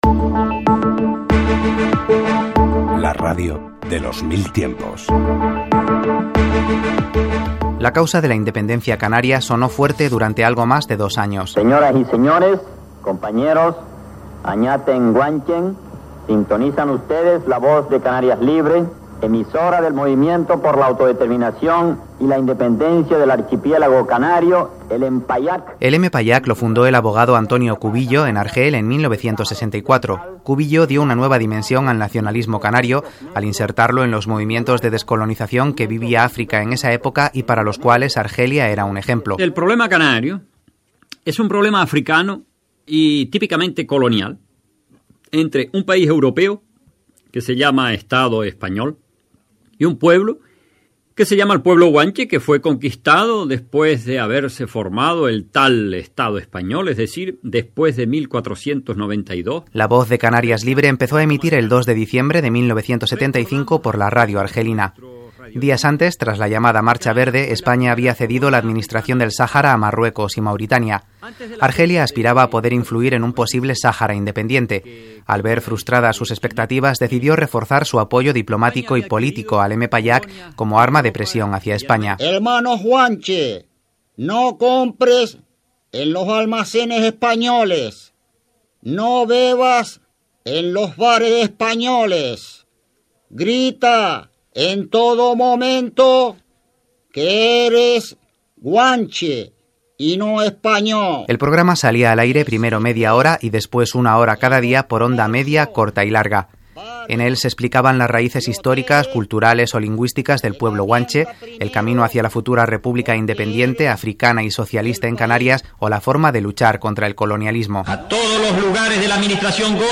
Careta del programa i espai dedicat a La Voz de Canarias Libre
Divulgació